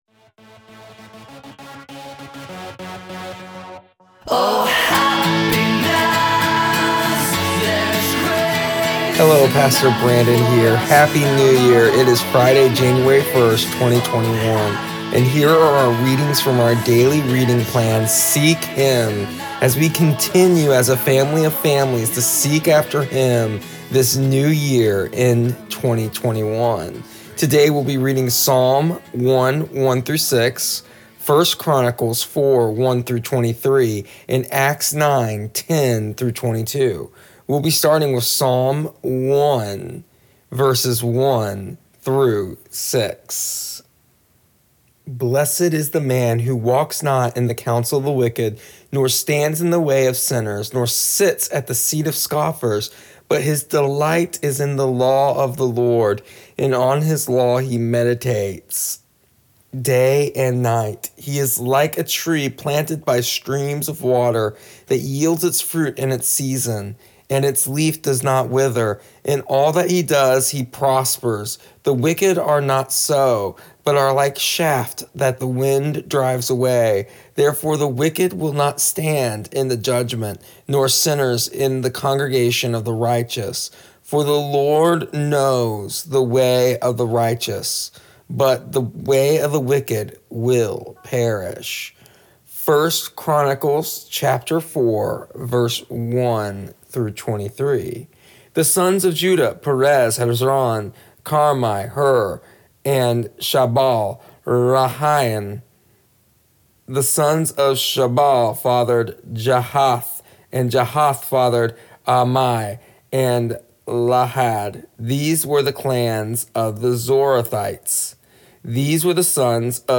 Here is the audio version of our daily readings from our daily reading plan Seek Him for January 1st, 2021.